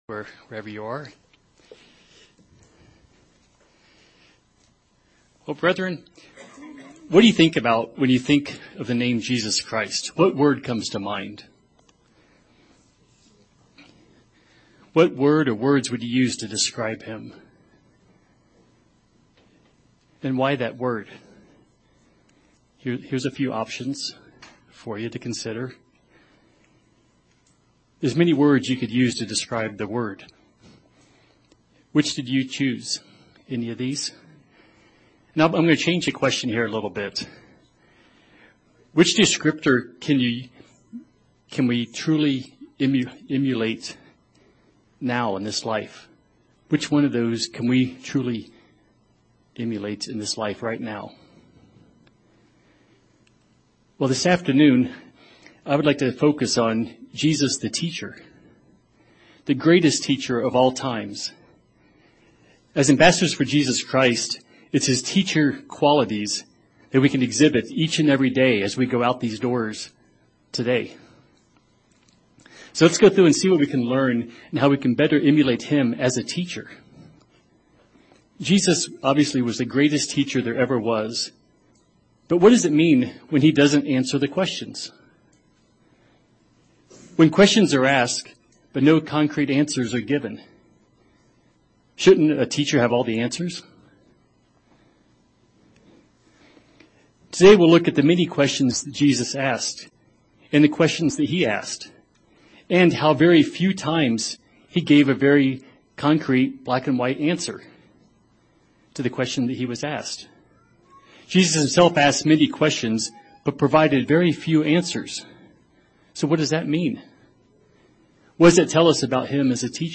Sermons
Given in Colorado Springs, CO Denver, CO Loveland, CO